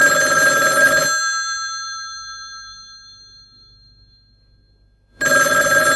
American 1950s Telephone Bell Ringtone
This is a genuine 1950s recording of a telephone bell not a digitally generated copy.